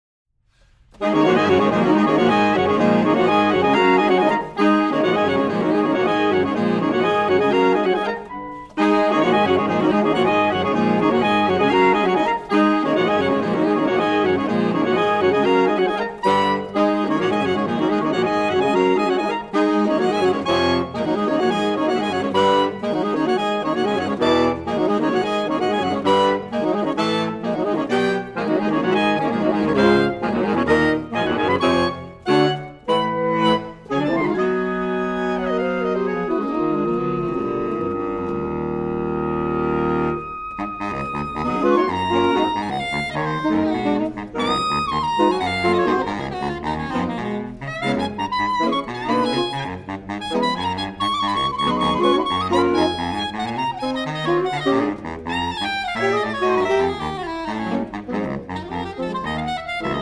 Saxophone Ensemble
Soprano Saxophone
Alto Saxophone
Tenor Saxophone
Baritone Saxophone